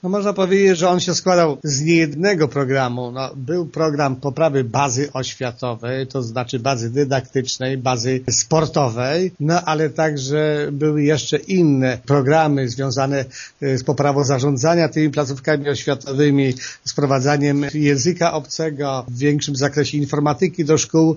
Zastępca wójta Wiktor Osik przypomina jednak, że w planie był zawarty nie tylko program inwestycji: